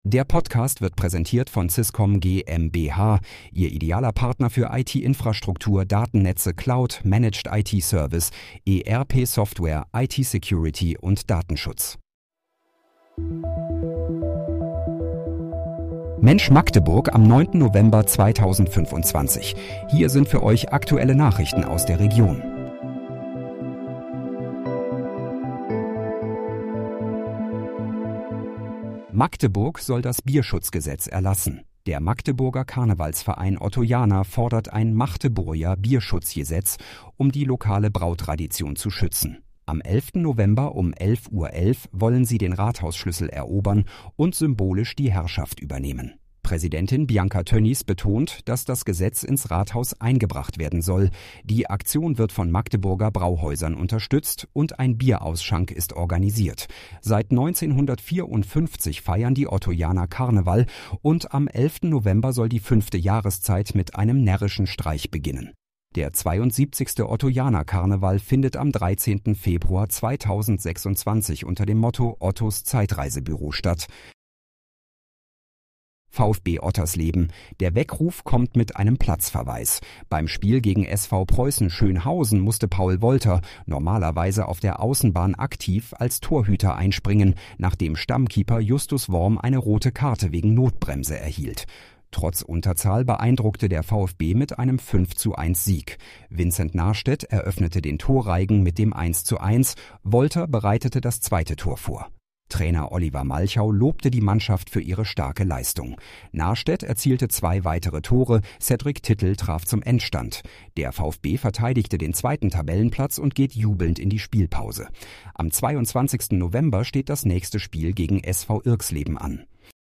Mensch, Magdeburg: Aktuelle Nachrichten vom 09.11.2025, erstellt mit KI-Unterstützung
Nachrichten